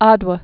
(ädwə, ăd-) also A·du·wa or A·do·wa də-wə, ădə-)